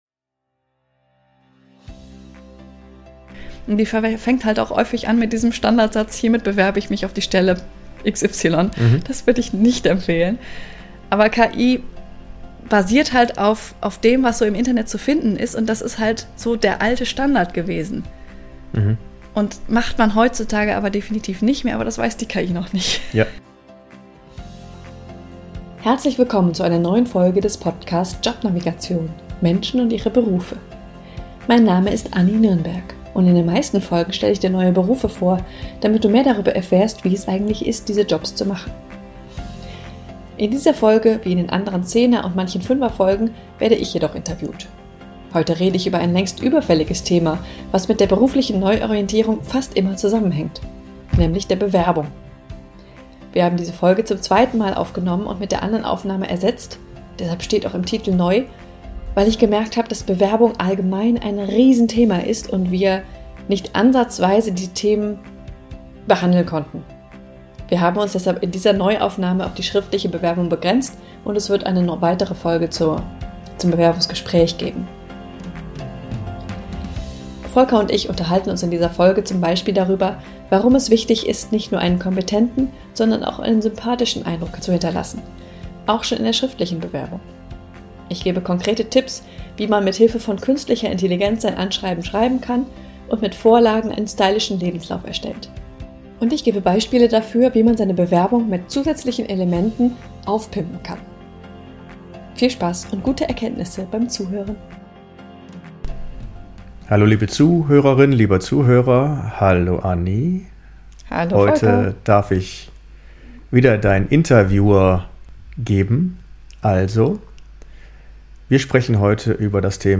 Wir haben diese Folge zum zweiten Mal aufgenommen und mit der anderen Aufnahme ersetzt (deshalb steht im Titel NEU), weil ich gemerkt habe, dass Bewerbung allgemein ein Riesenthema ist.